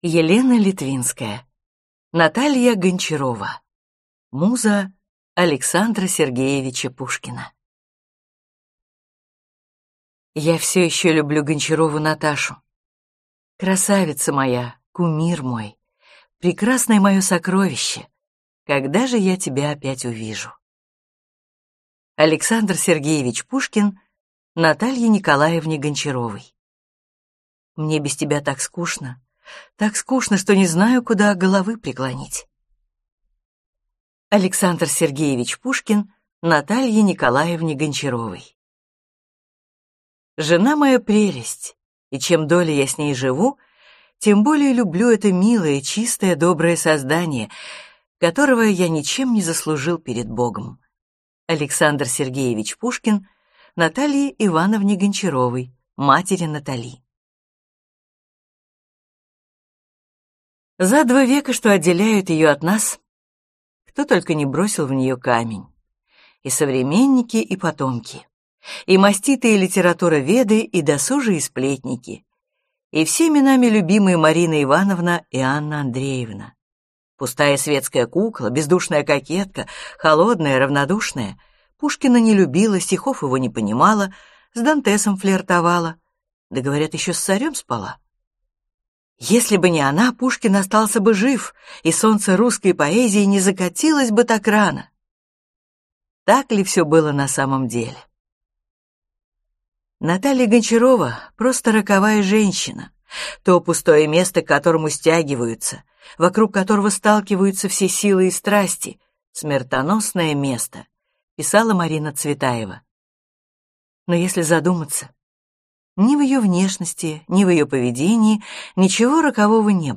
Аудиокнига Наталья Гончарова.